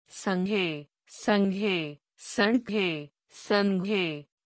In any event, I have now made the two dotted m’s sound the same.
The first two are soooo close but not.
saghe (not a real word)
sa n ghe (not a real word)